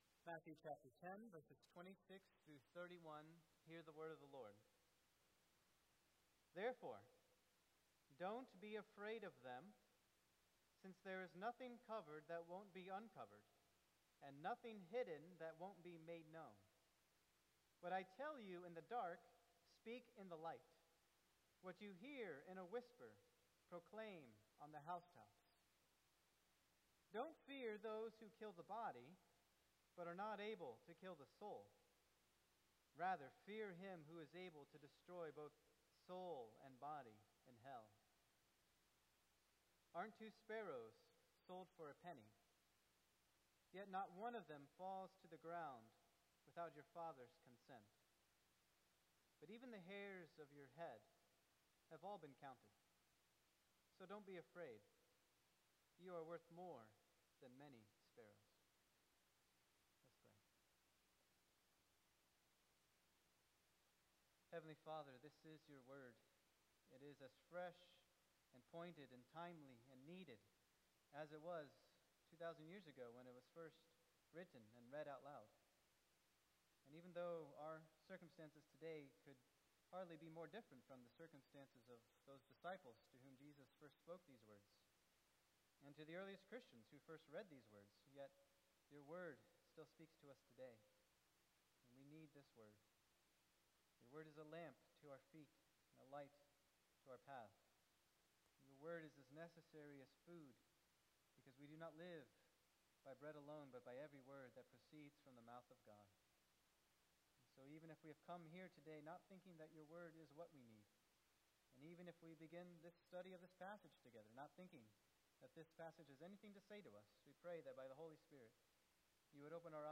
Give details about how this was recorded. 2025 at First Baptist Church in Delphi, Indiana.